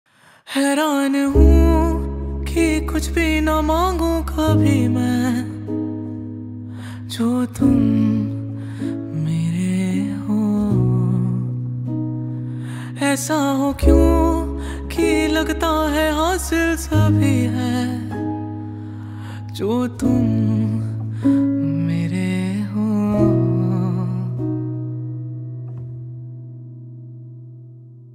• Simple and Lofi sound
• High-quality audio
• Crisp and clear sound